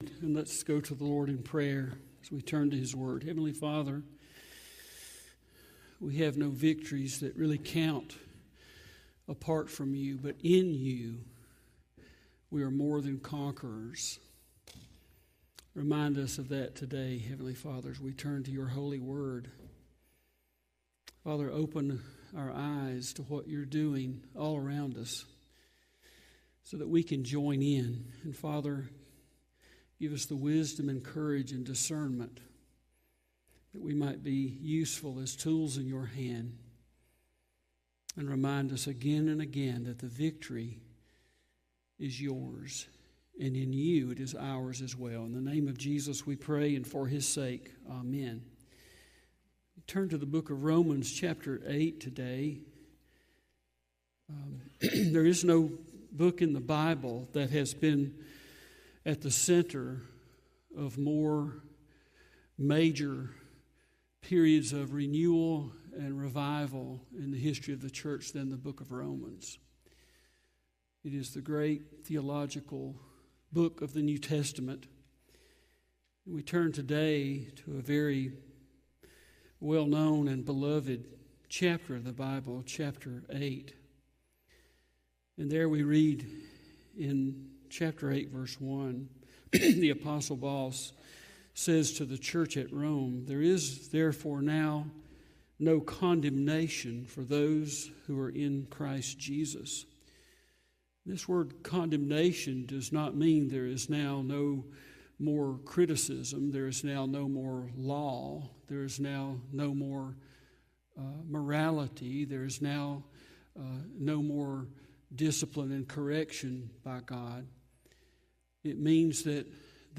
Sermons | Smoke Rise Baptist Church